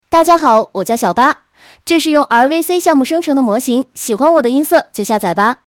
吉伊卡哇（chiikawa）小八 RVC模型